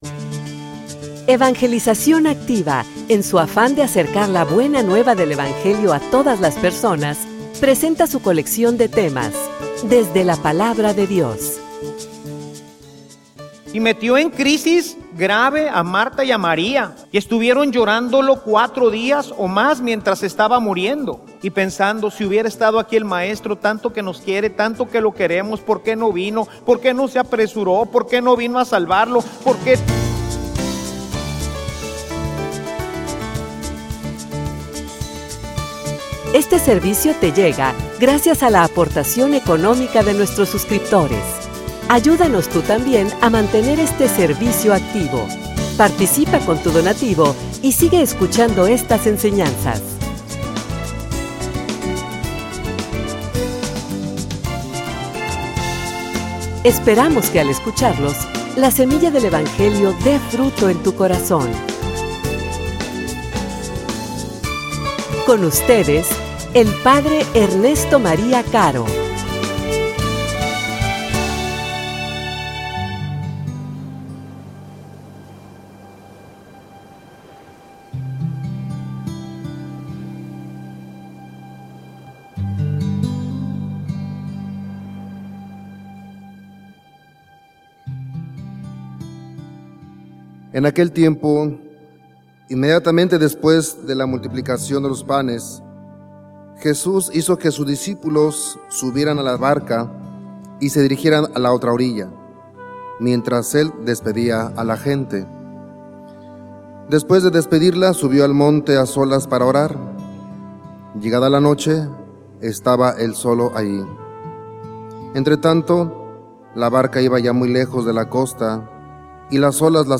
homilia_Sube_a_Dios_a_tu_barca.mp3